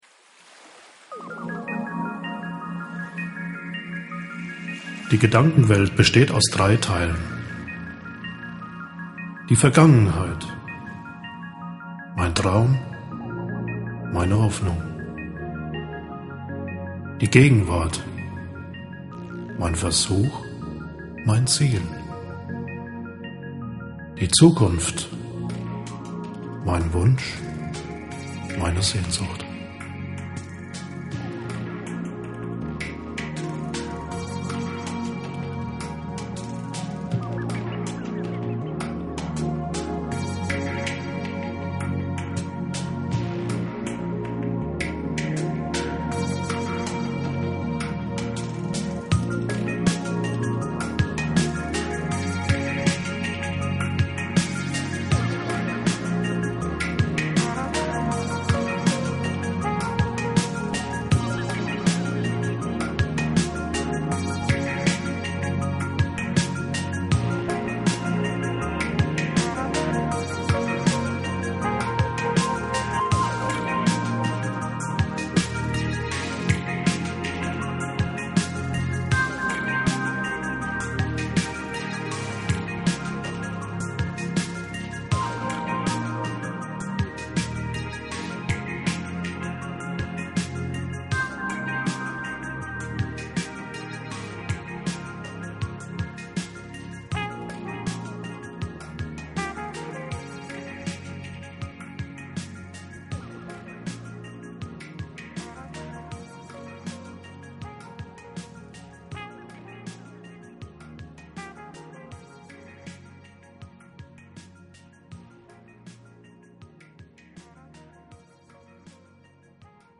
Der Klang seiner Stimme wirkt beruhigend und führt die Zuhörer in eine andere Welt voller Träume, Sehnsüchte und Verlangen nach Liebe.